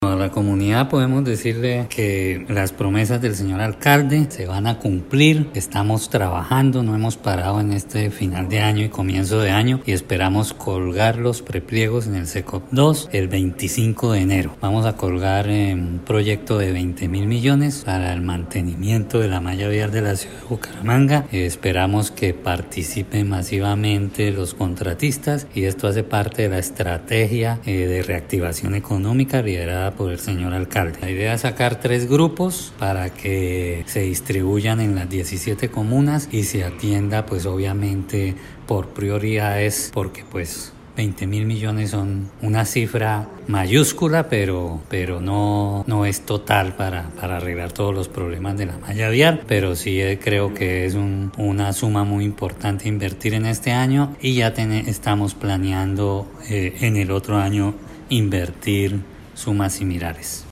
Descargue audio: Iván Vargas, secretario de infraestructura